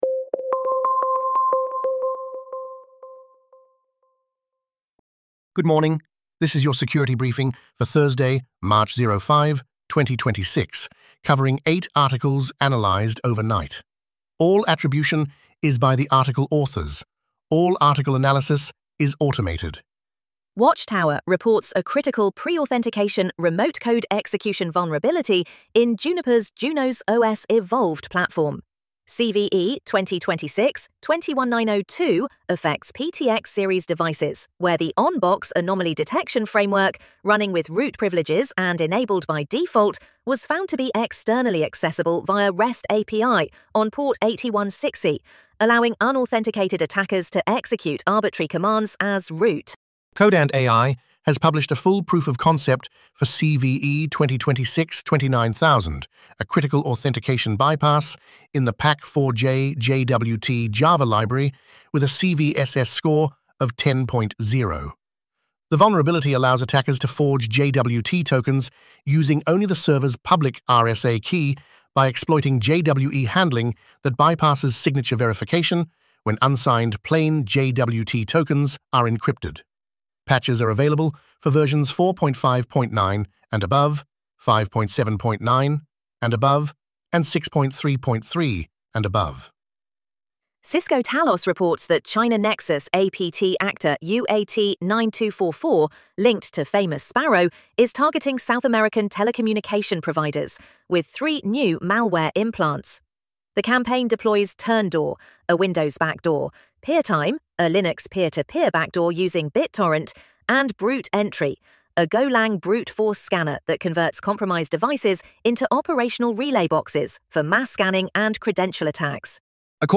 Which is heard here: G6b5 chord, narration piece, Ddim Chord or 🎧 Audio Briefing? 🎧 Audio Briefing